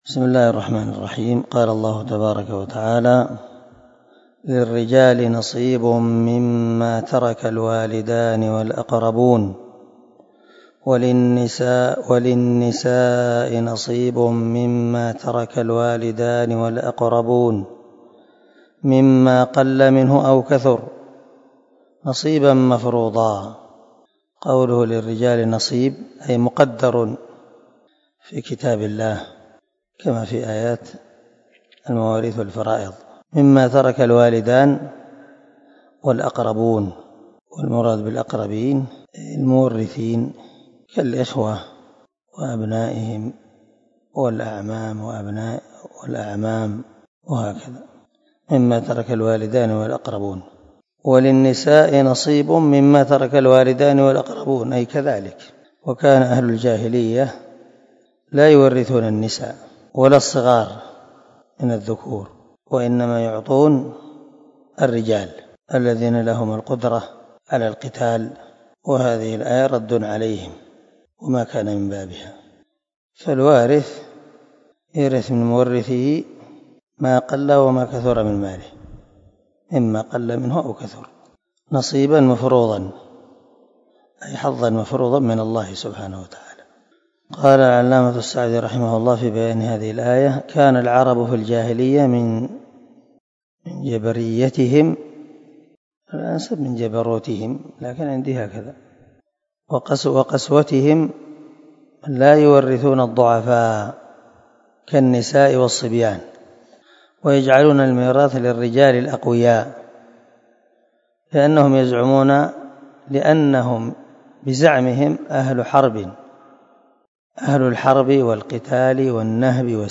سلسلة_الدروس_العلمية
دار الحديث- المَحاوِلة- الصبيحة